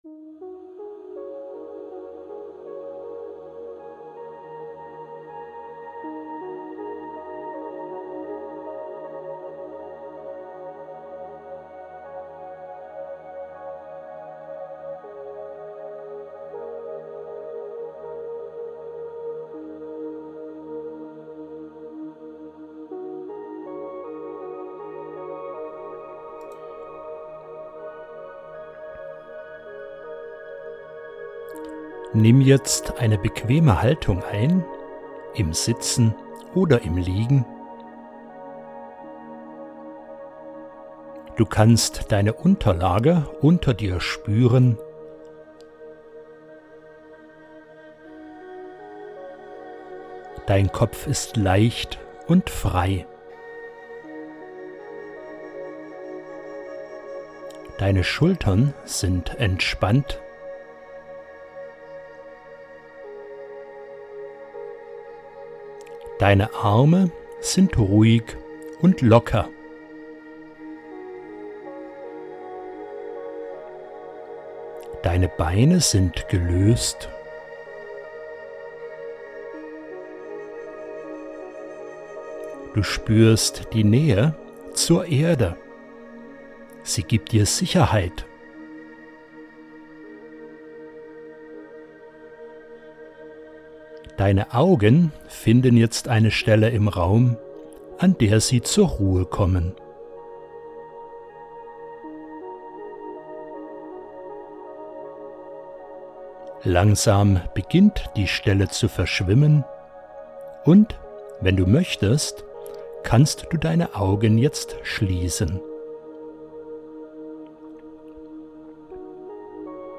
GEDANKENREISE IN DEN WINTERWALD
Gedankenreise-Winterwald.mp3